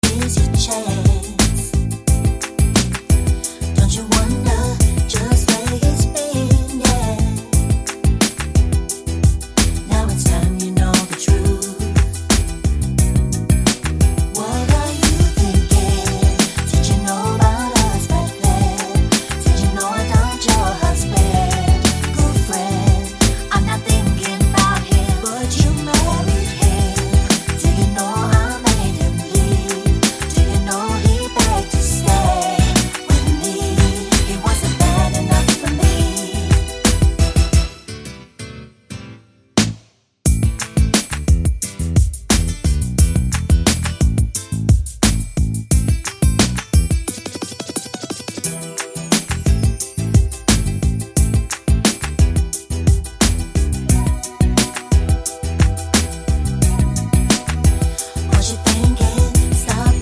no intro vox R'n'B / Hip Hop 4:23 Buy £1.50